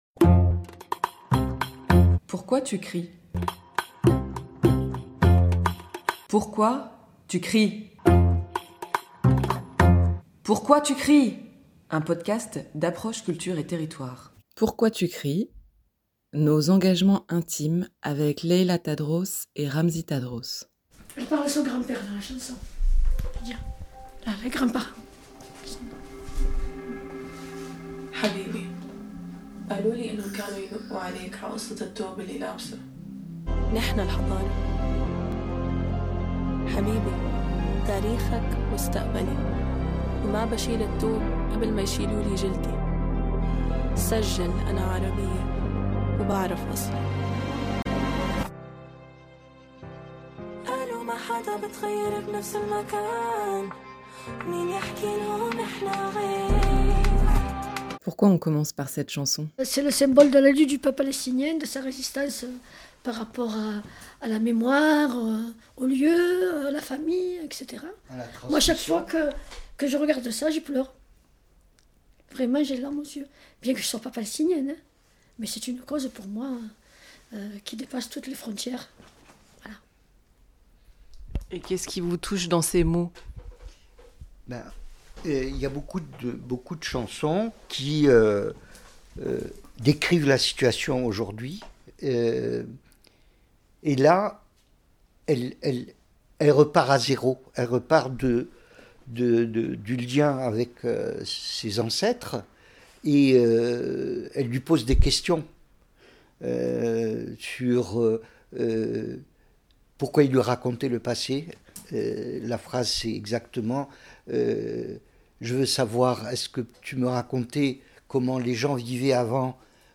Une discussion où s’entremêlent des musiques actuelles qui évoquent aux deux militants la transmission des luttes et la mémoire.